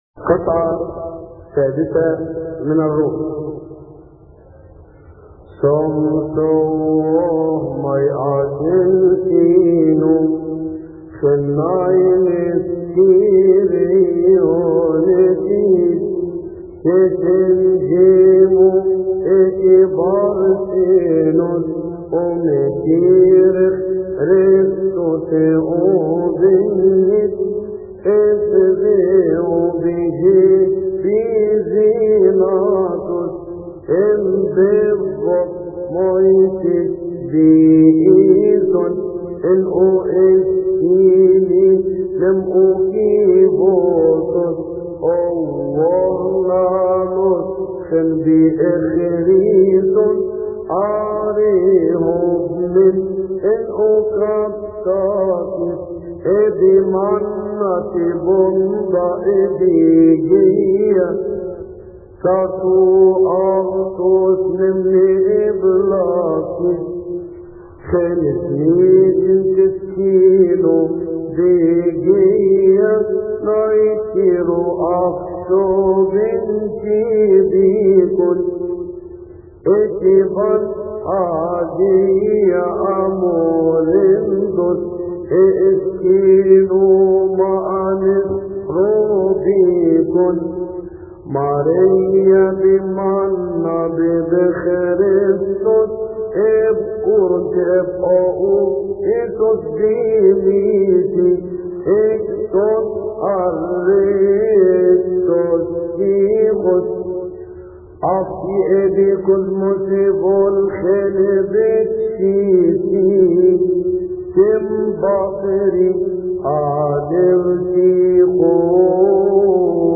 التفسير السادس من الرومي لثيؤطوكية السبت يصلي في تسبحة عشية أحاد شهر كيهك